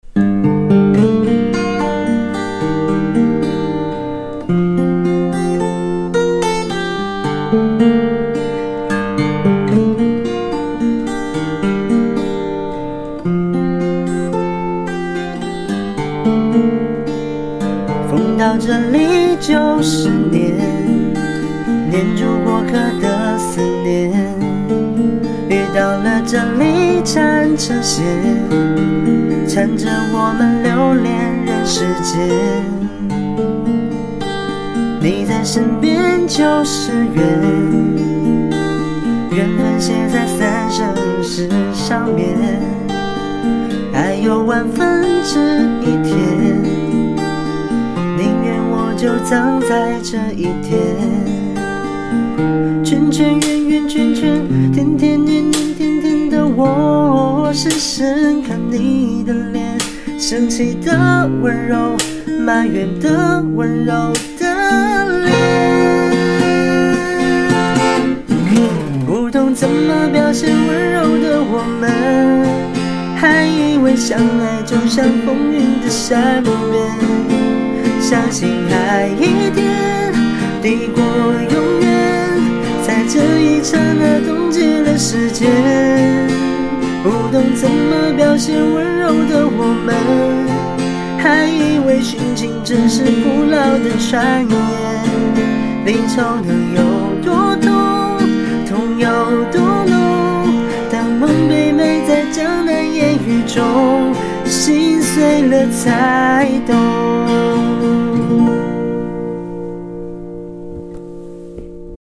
3品G调指法